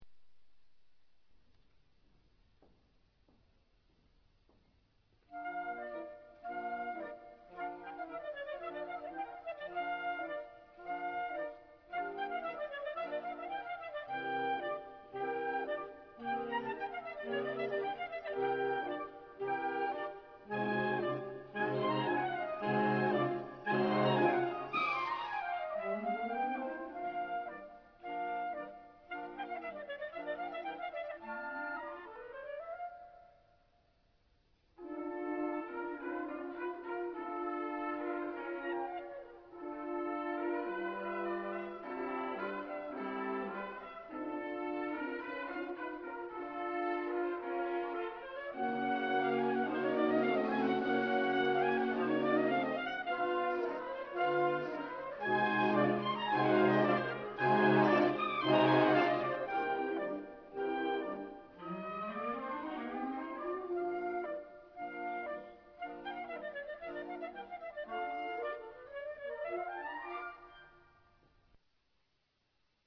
地點: 音乾乾的中山堂